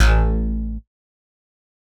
Index of /RoBKTA Sample Pack Supreme/BASSES